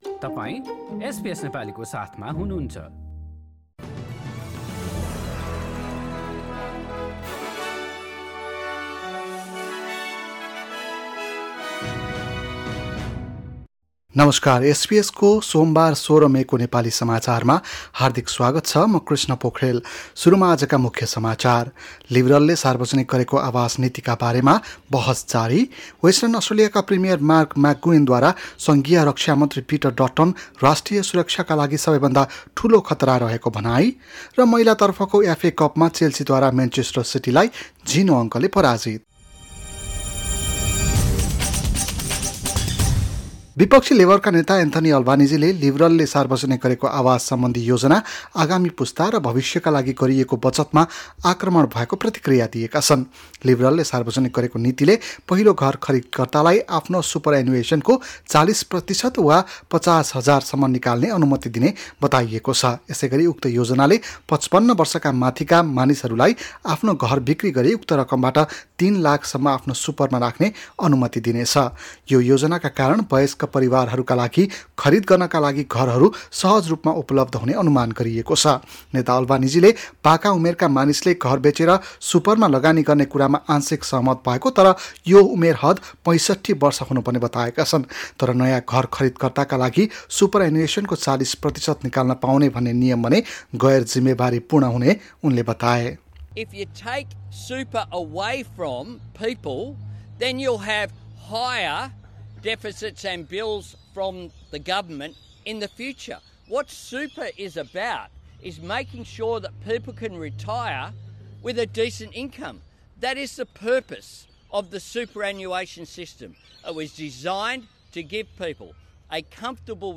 एसबीएस नेपाली अस्ट्रेलिया समाचार: सोमबार १६ मे २०२२